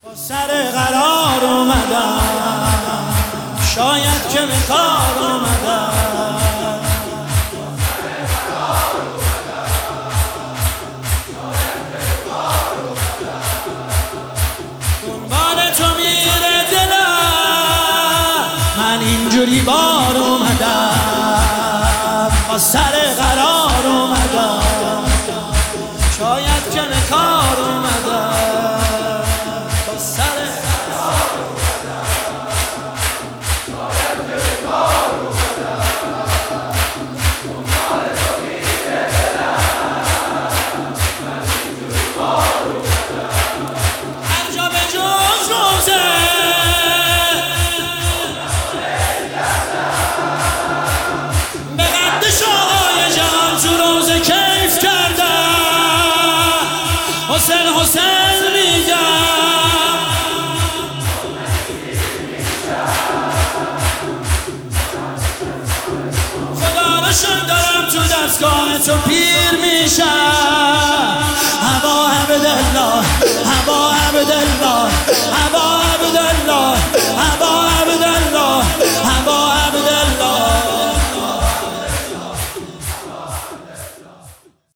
شهادت امام صادق (ع) 1404